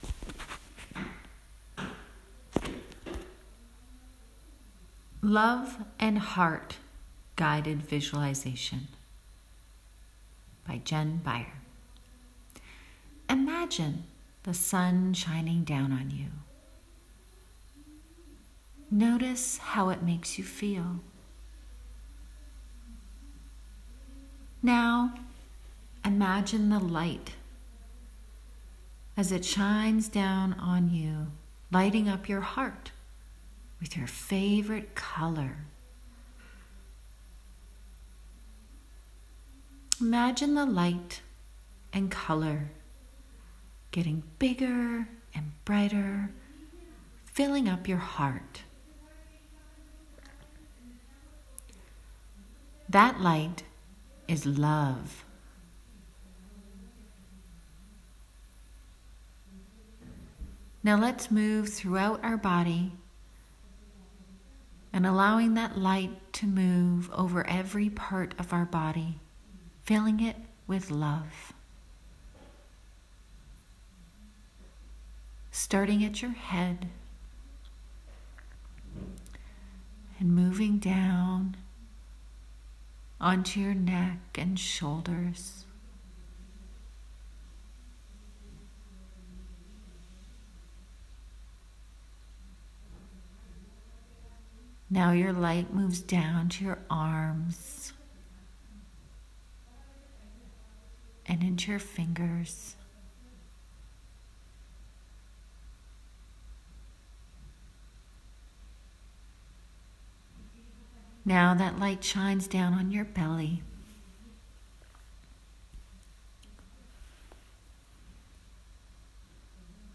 Love & Heart Guided Visualization
Love-Heart-Guided-Meditation..m4a